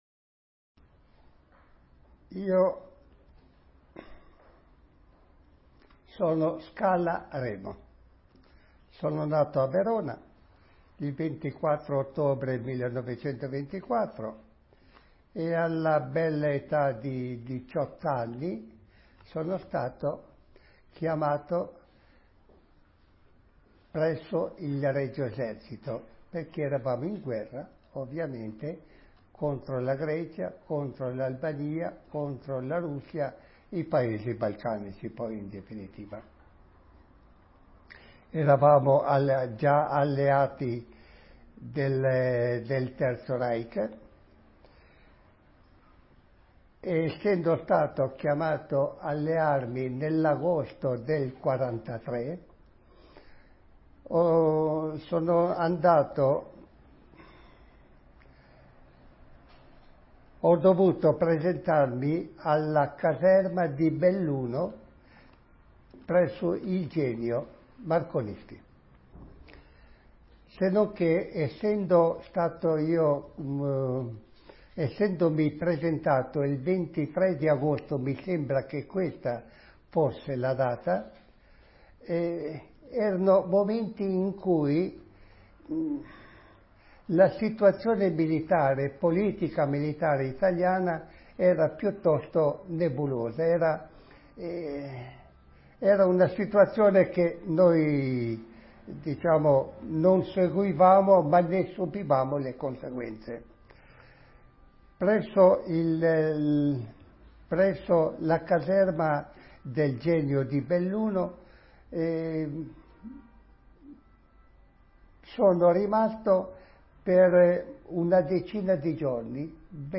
Intervista del
a Torino